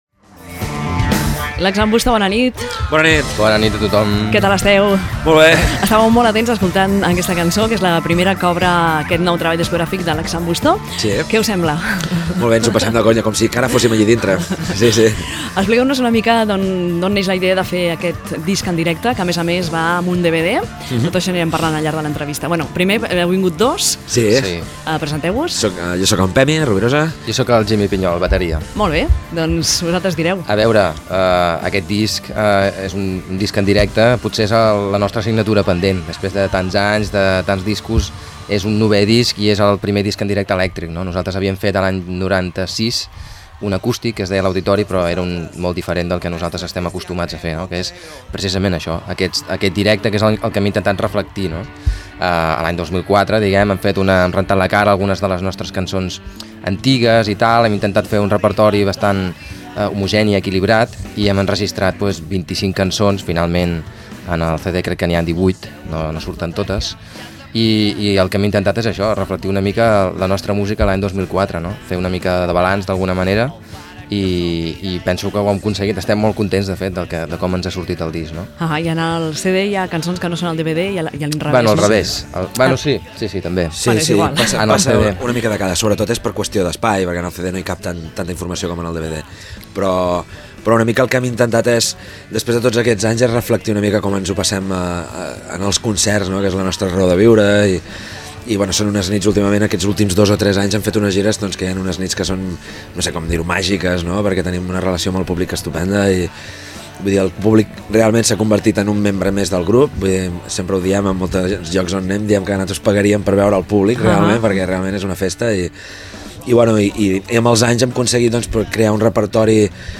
Entrevista Lax'n'Busto - Cadena100, 2004